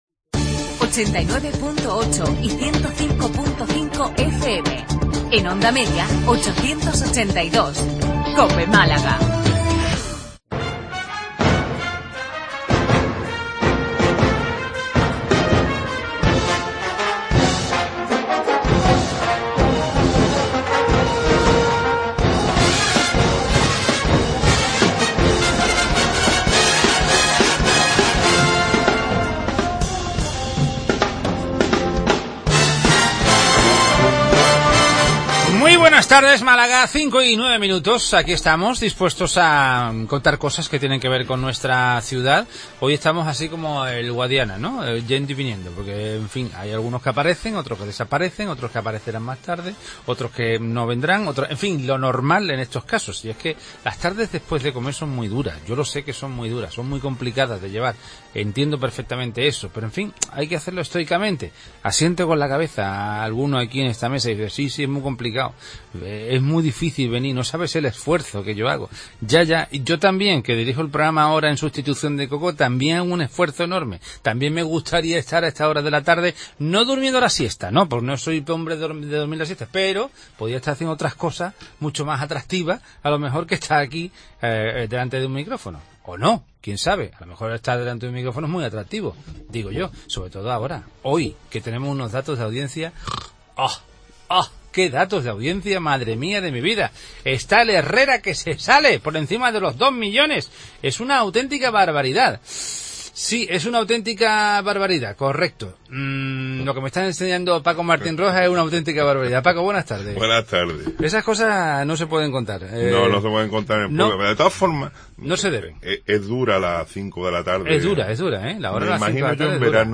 AUDIO: Tertulia de los temas que interesan a Málaga y los Malagueños.